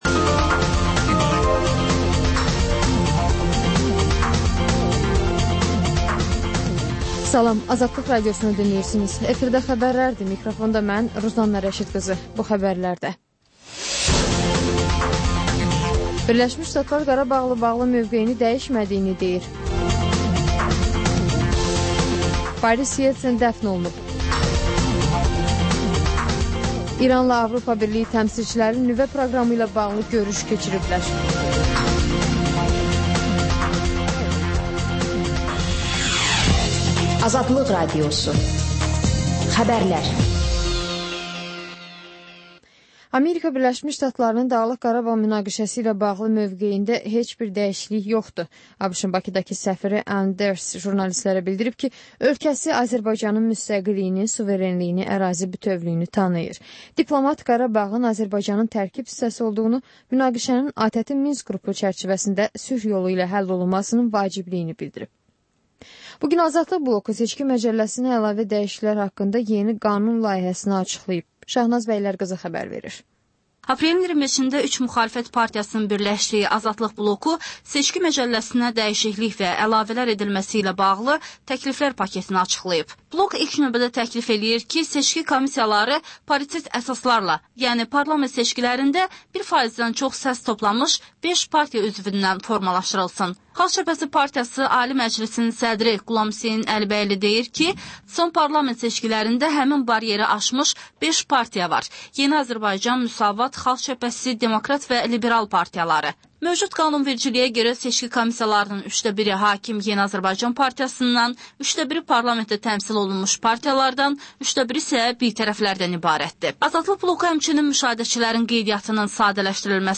Xəbərlər, müsahibələr, hadisələrin müzakirəsi, təhlillər